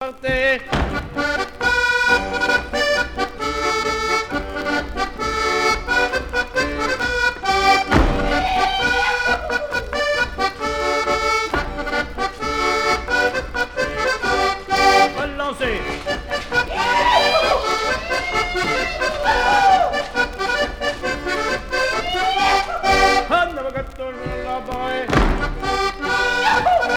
Bocage vendéen
danse : quadrille : avant-quatre
Pièce musicale éditée